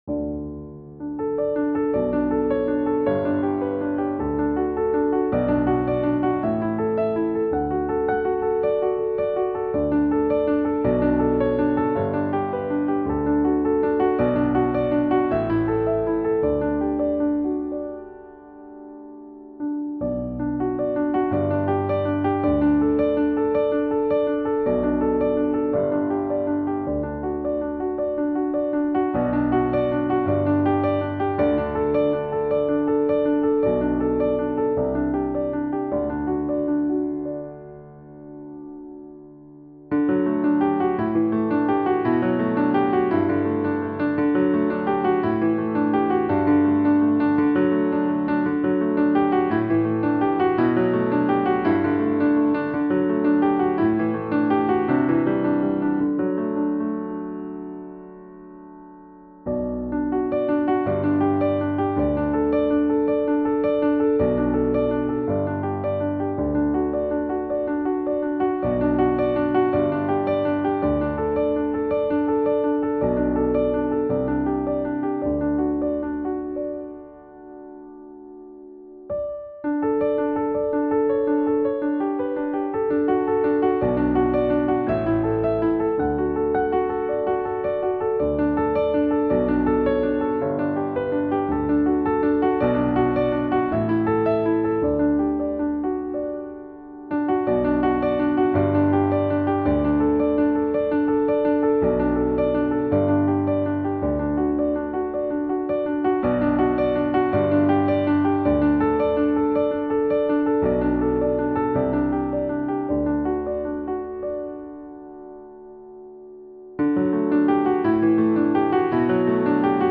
Listen to me playing